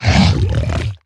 Minecraft Version Minecraft Version 25w18a Latest Release | Latest Snapshot 25w18a / assets / minecraft / sounds / mob / zoglin / attack2.ogg Compare With Compare With Latest Release | Latest Snapshot
attack2.ogg